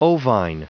Prononciation du mot ovine en anglais (fichier audio)
Prononciation du mot : ovine